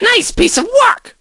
crow_kills_01.ogg